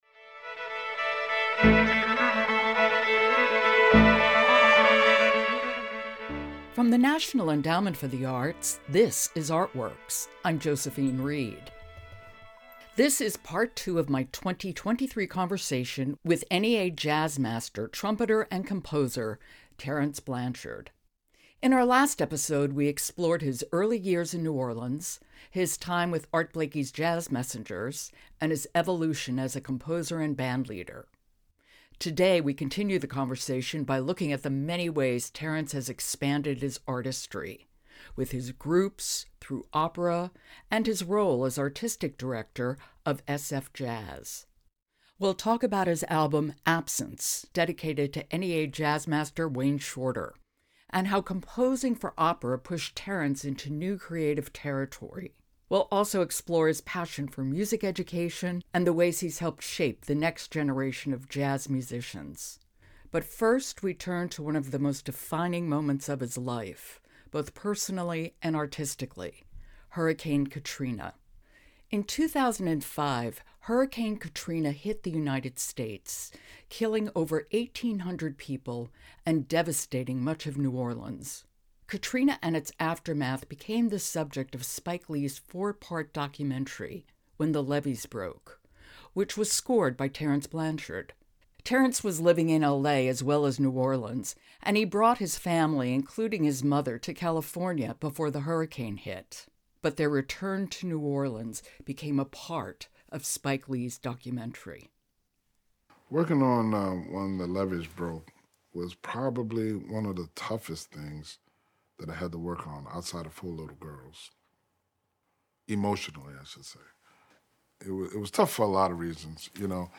In the second part of our conversation with 2024 NEA Jazz Master, trumpeter, and composer Terence Blanchard, we explore the emotional and creative depths of his work, from his jazz innovations to his evolution as an opera composer.